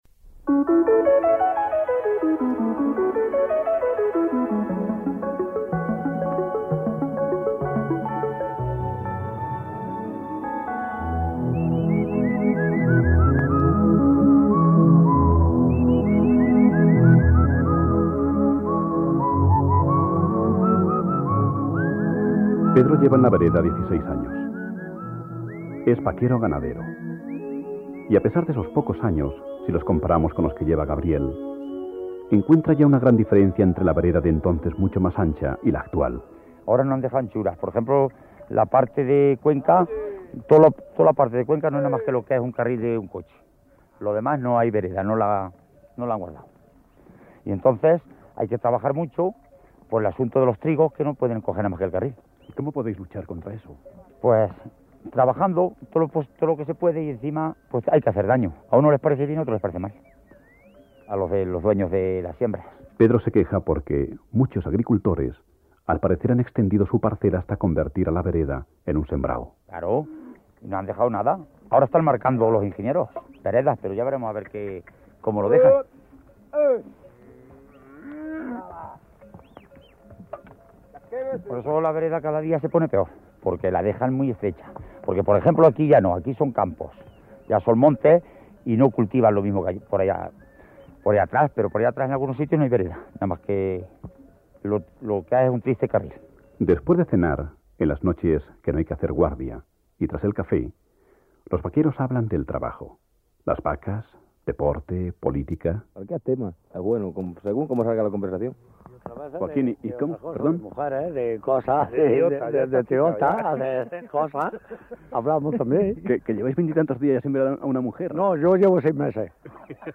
Programa de radio (“Mi tierra, mi gente”) realizado el 12 de noviembre de 1986
TRASHUMANCIA-MP3-REEDITADA-2025.mp3